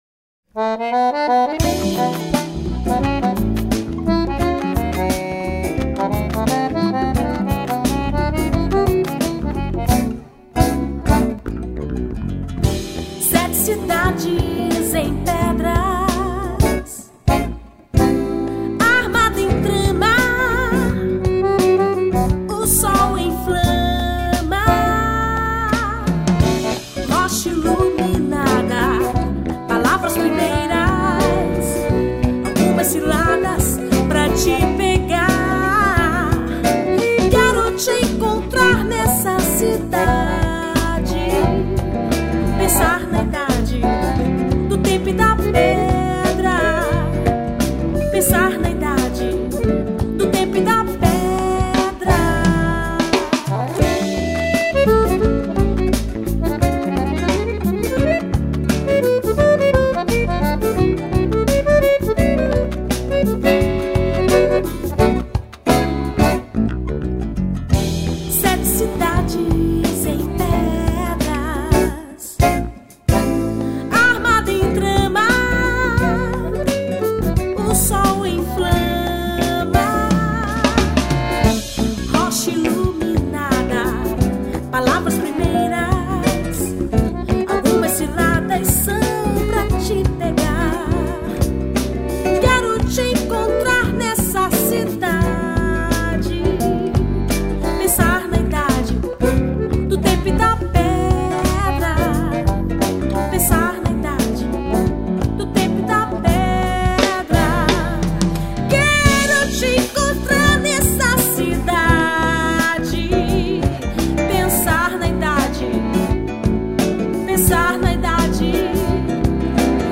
90   02:34:00   Faixa:     Mpb